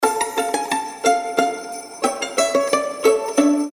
без слов
скрипка